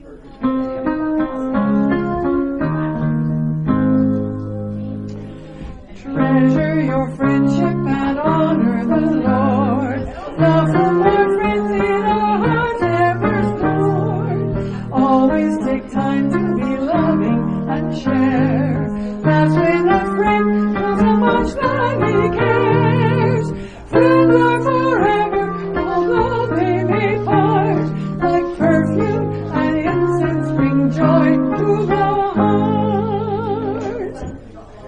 Hymn-1.mp3